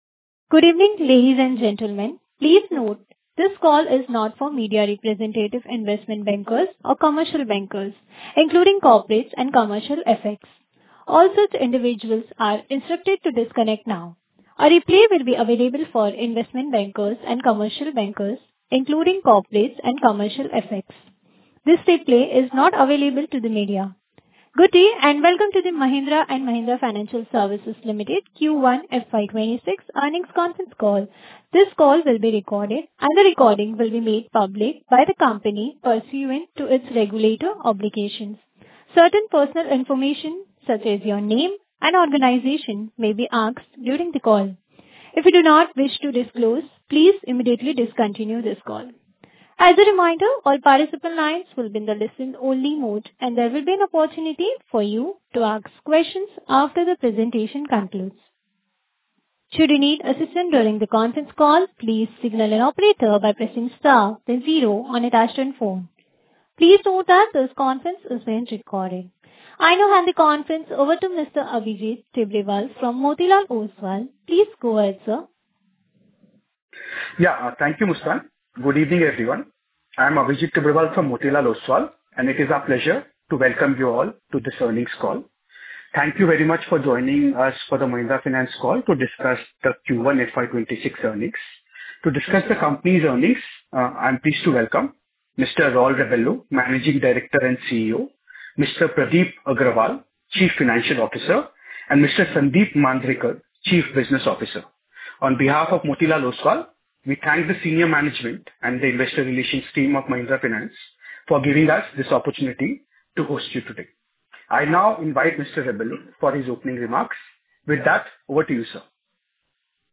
Mahindra Finance Q2 FY26 Concall Audio
Q1-FY26-Concall-Audio.mp3